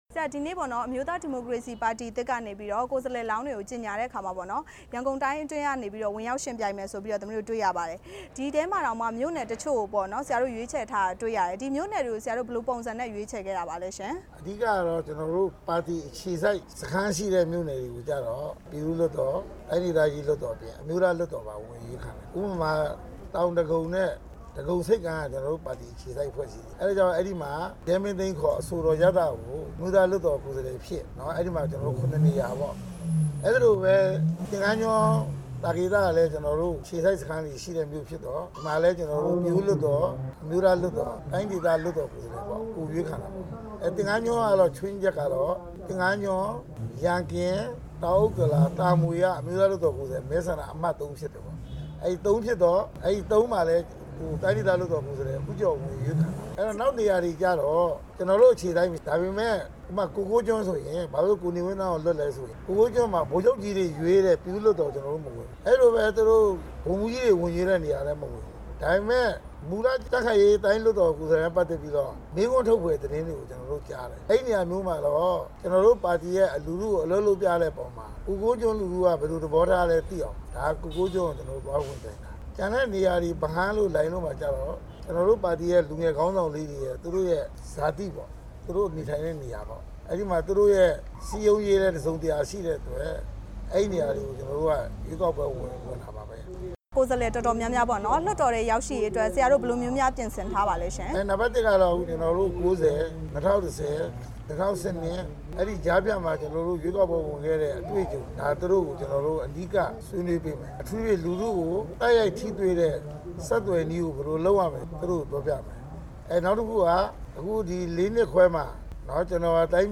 တွေ့ဆုံမေးမြန်း ထားပါတယ်။